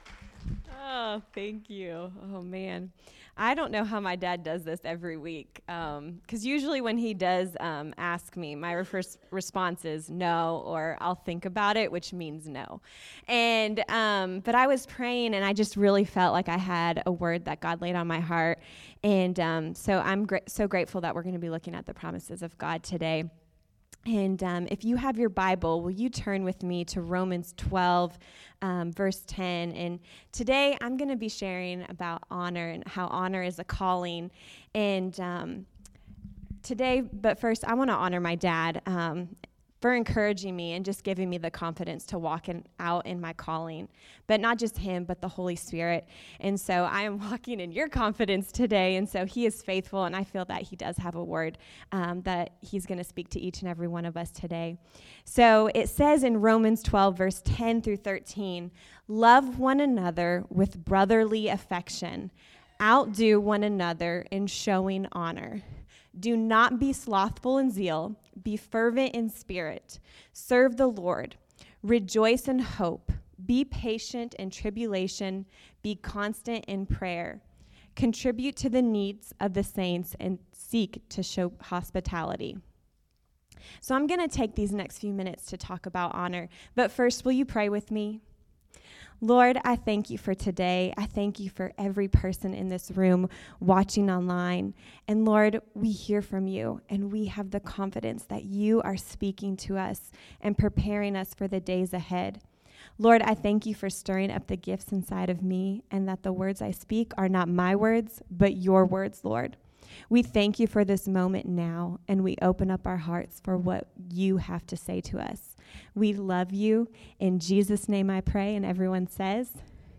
Today’s sermons will give some practical advice on how to honor those around us, even when we might disagree with them.